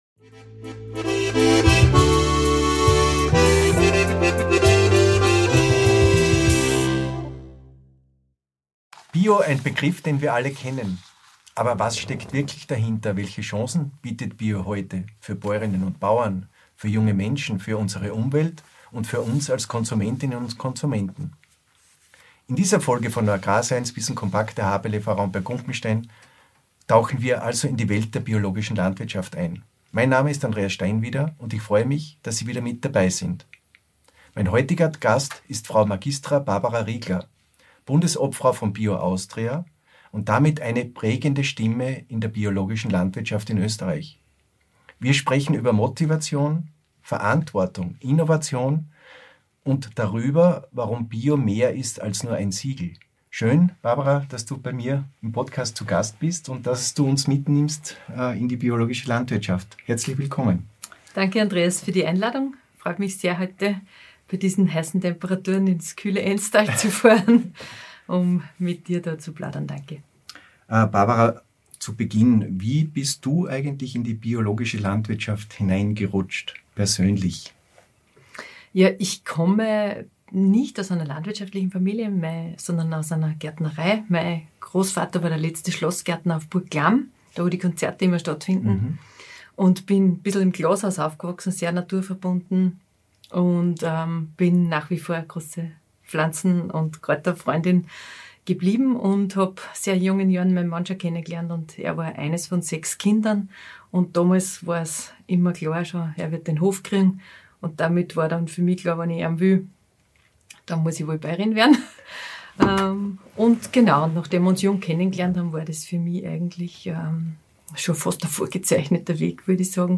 Ein Gespräch über Verantwortung, Qualität – und darüber, warum Bio für uns alle Zukunft hat.